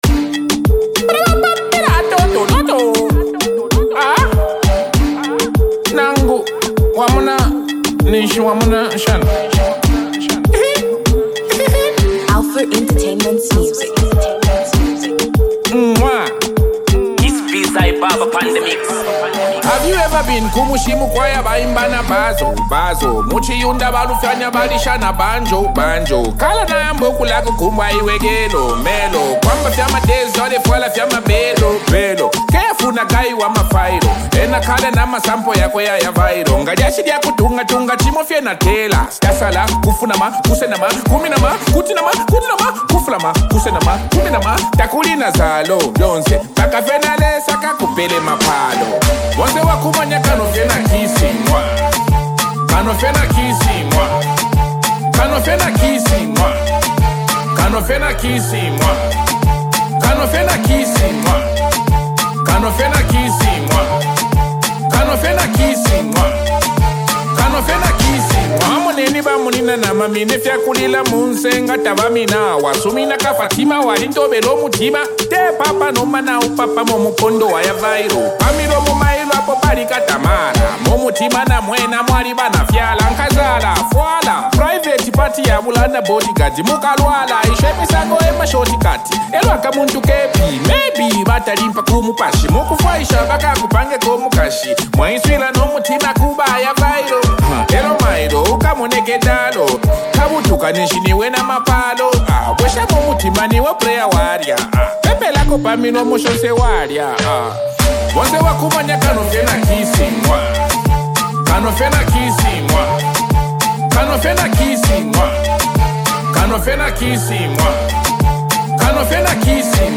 a bold, unapologetic street anthem